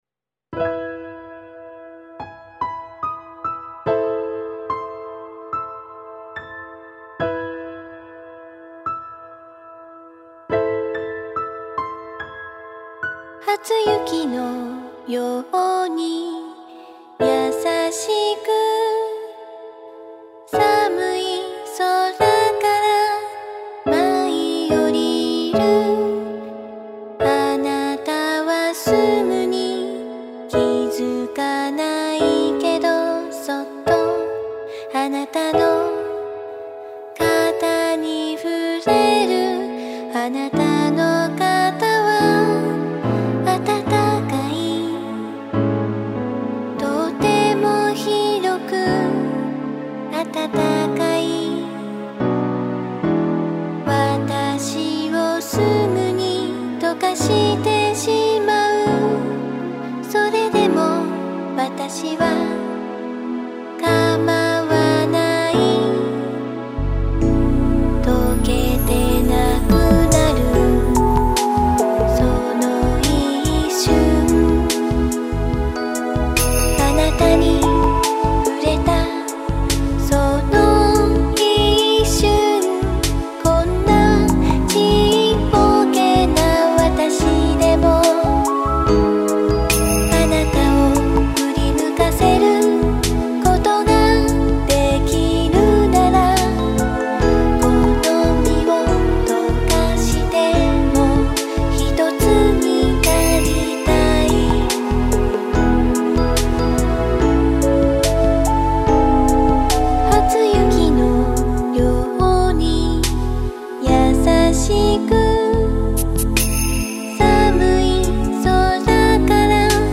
・使用音源：YAMAHA motif-RACK